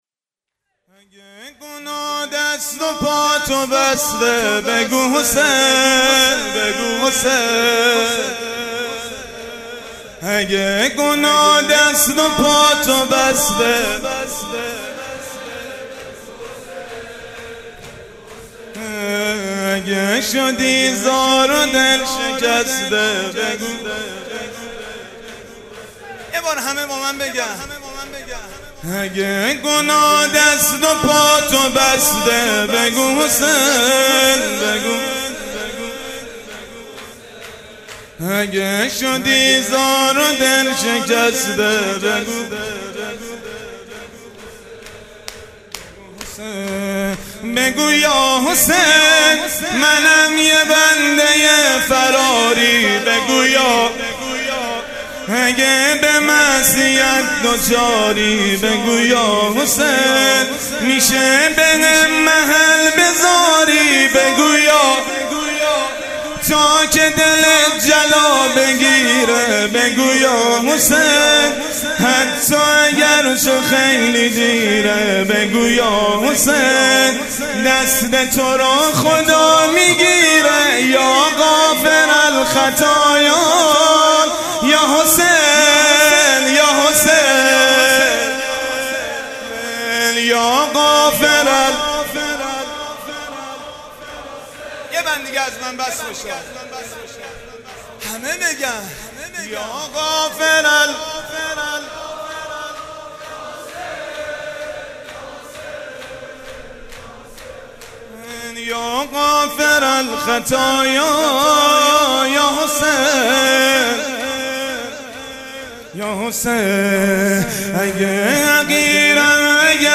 مداحی های شب بیست و ششم ماه مبارک رمضان در هیئت مکتب الزهرا(س)
شور/شب بیست و ششم ماه رمضان